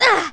pain6.wav